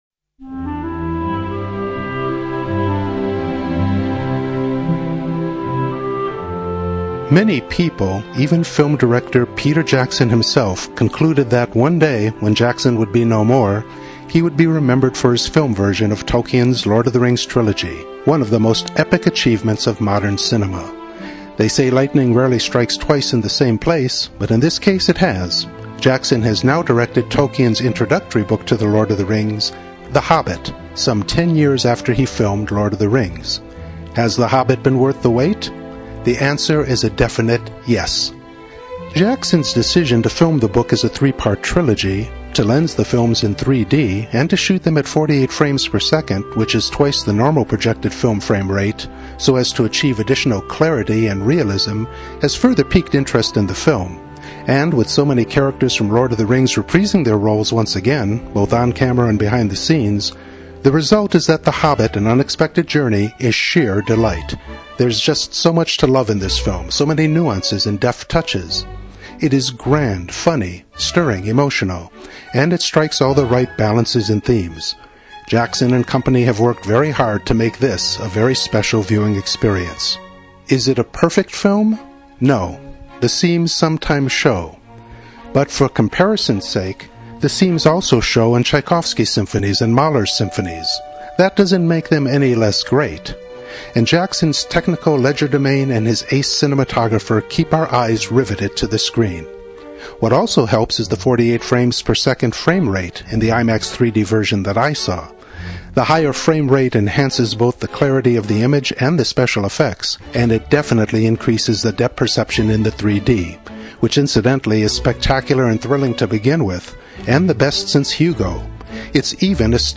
Review: THE HOBBIT: AN UNEXPECTED JOURNEY (2012)